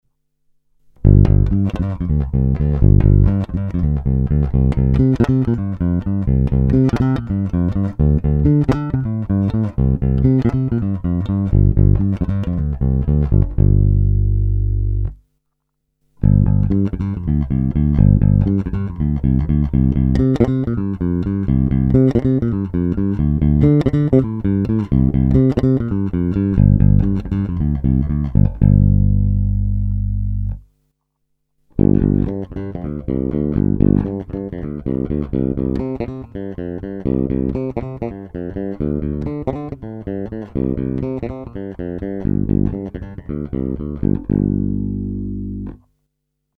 Naprosto typický Jazz Bass, ve zvuku hodně vnímám rezonanci samotného nástroje a desítky let jeho vyhrávání.
Není-li uvedeno jinak, následující nahrávky jsou vyvedeny rovnou do zvukovky, s plně otevřenou tónovou clonou a jen normalizovány, jinak ponechány bez úprav.